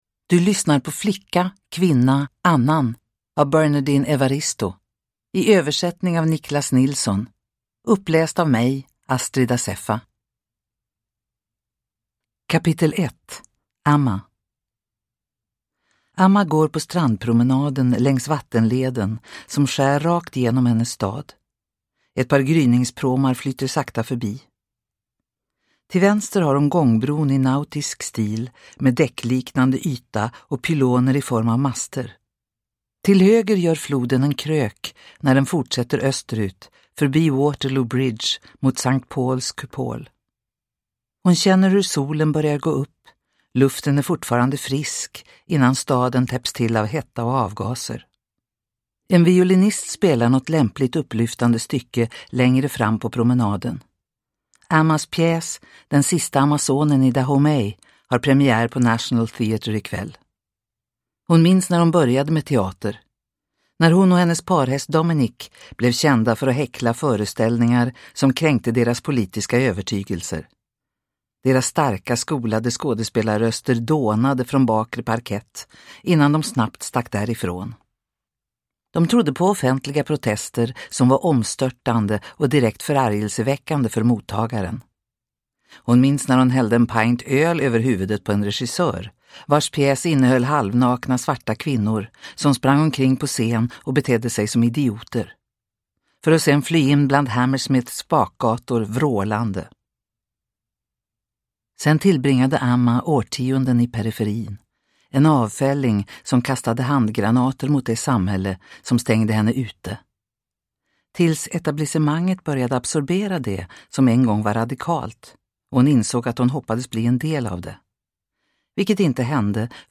Flicka, kvinna, annan – Ljudbok – Laddas ner